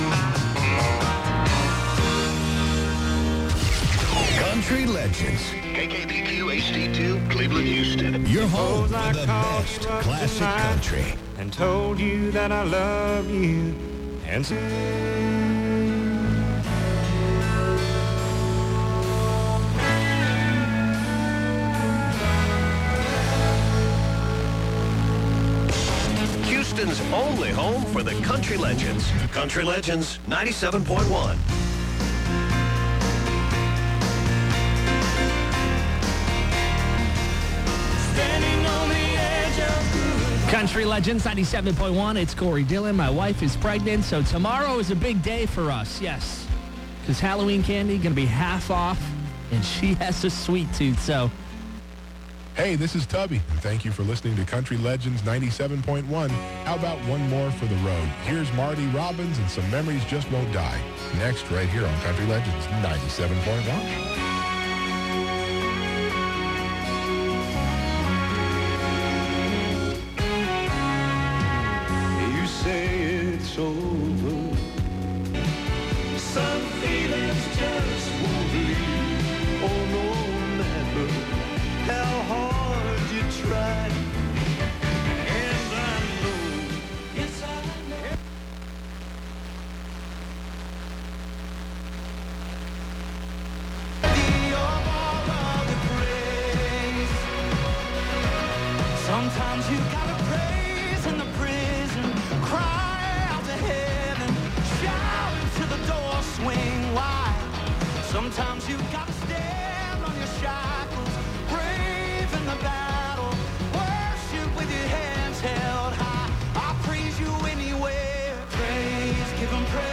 Previous Format: Classic Country “Country Legends 97.1” KTHT
New Format: Christian AC